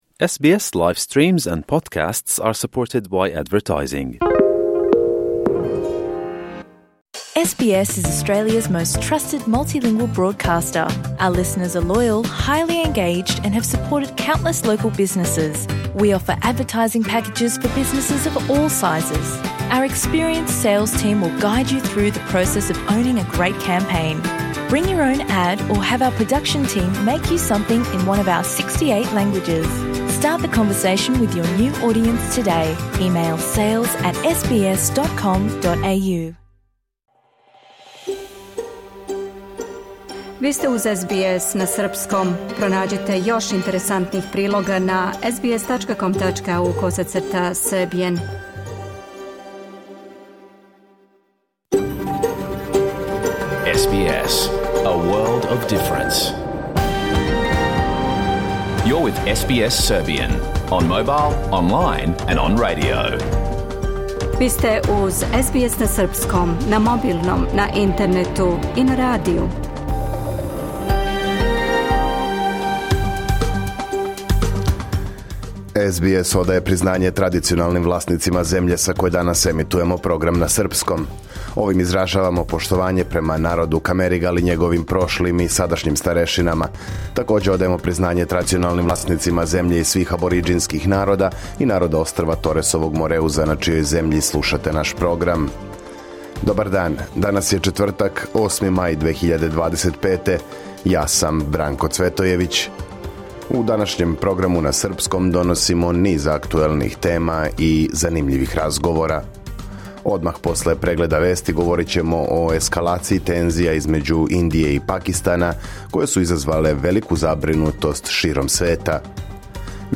Програм емитован уживо 8. маја 2025. године
Уколико сте пропустили данашњу емисију, можете је послушати у целини као подкаст, без реклама.